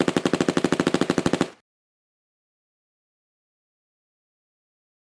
Carti X Ethereal Fx.wav